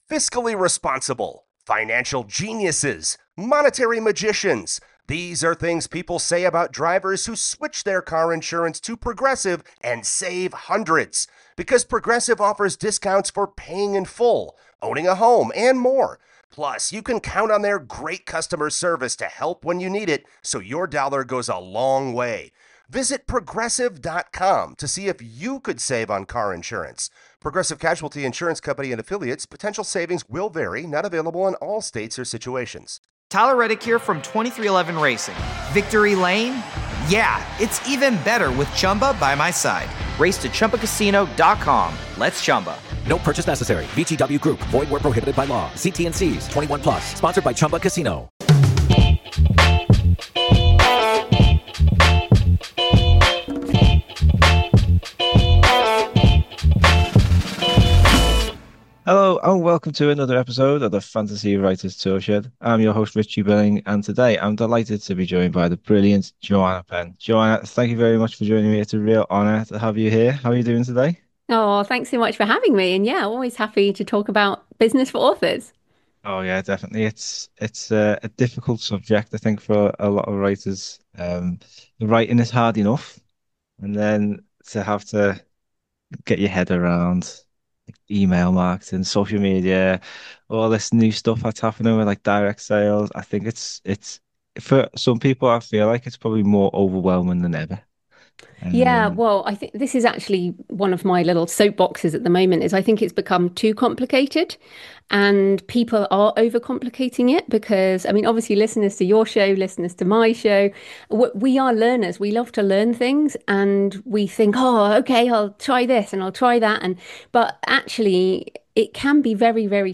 In this episode, I’m joined by bestselling author and entrepreneur Joanna Penn to explore how writers can build a sustainable writing business without losing their creative spark.